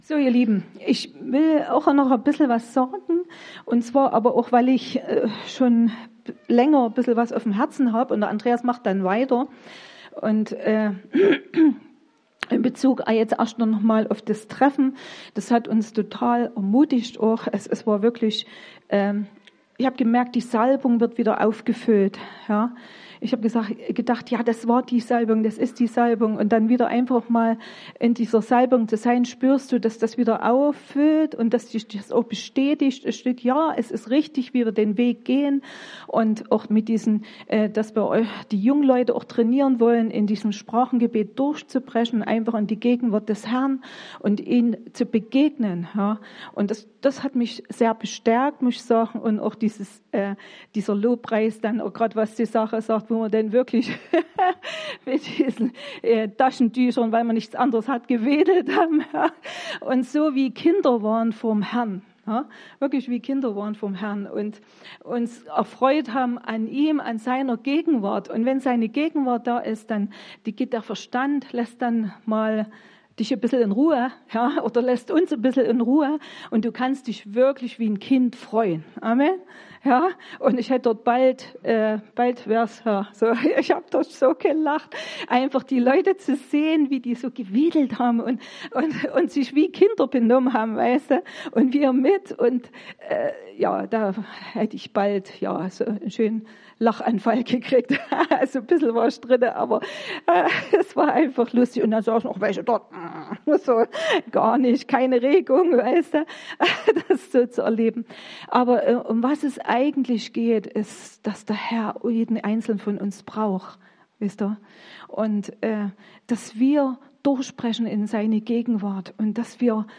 Predigten chronologisch sortiert